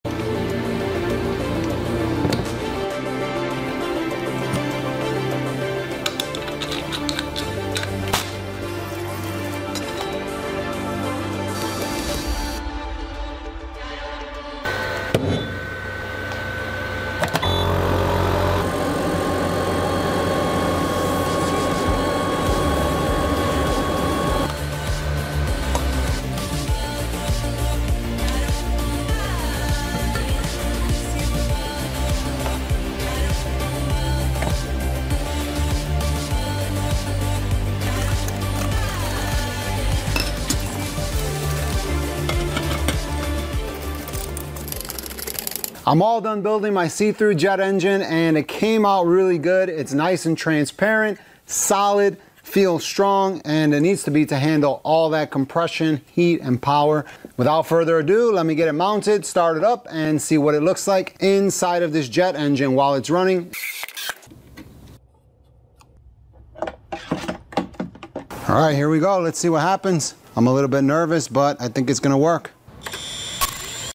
See Thru Jet Engine sound effects free download